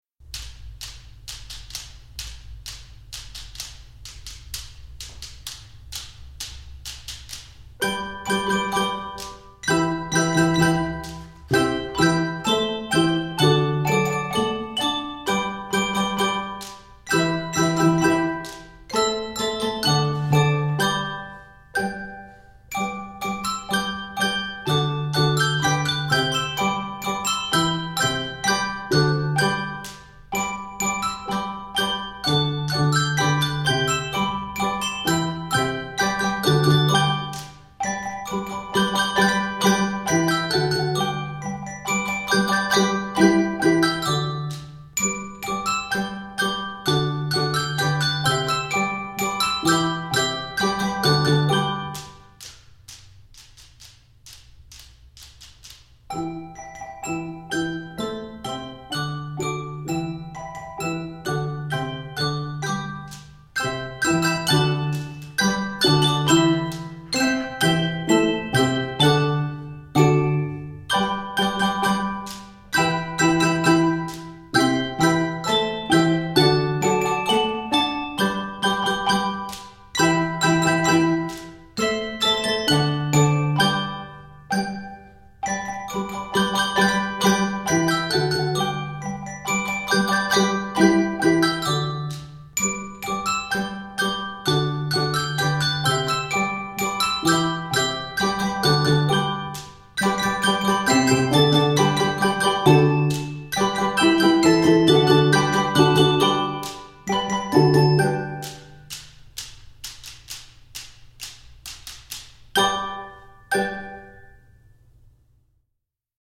A total of 62 measures, this work is written in G Major.